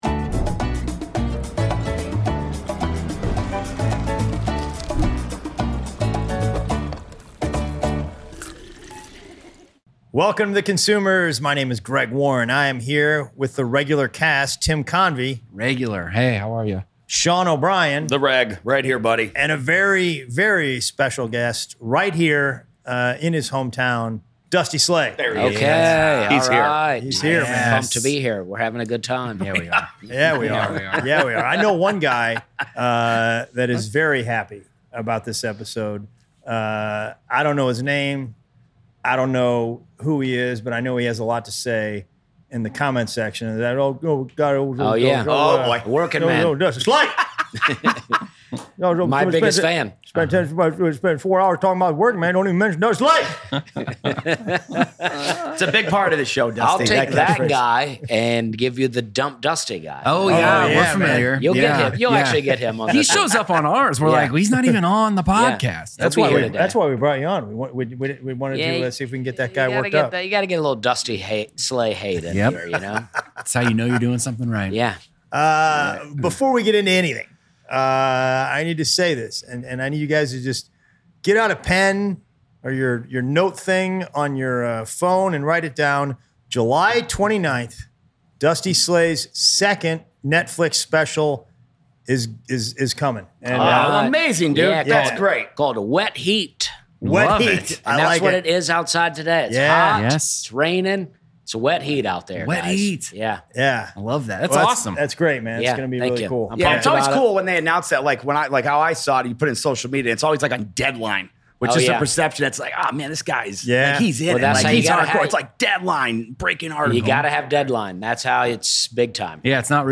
Another episode from Nashville.